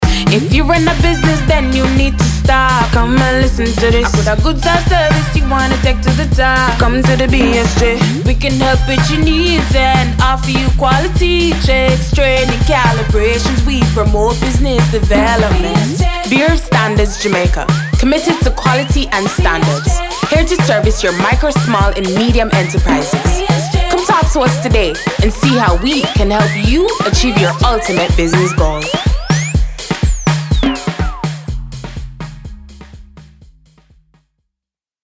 This dynamic advertisement brilliantly highlights the agency's unwavering dedication to elevating its services for businesses across the beautiful landscape of Jamaica, ensuring that every enterprise can thrive and reach its full potential.